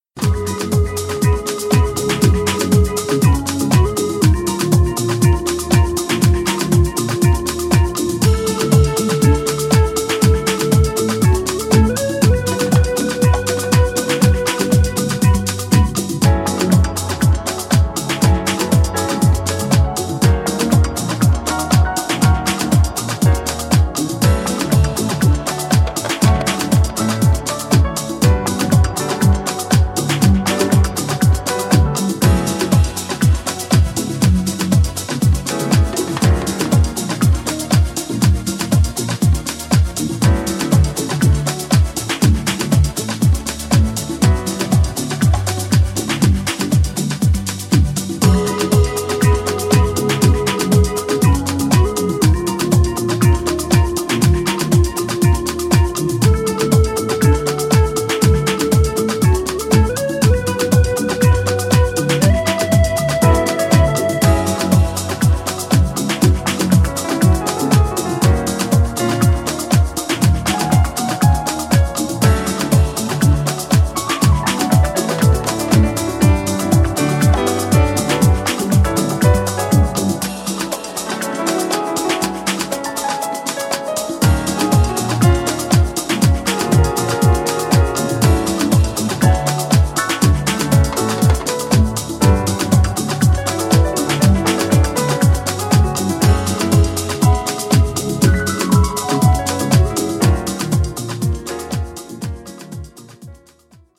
パーカッションやピアノ等がフィーチャーされた躍動感のあるトラックにソウルフルで丁寧に歌い上げるヴォーカルが◎！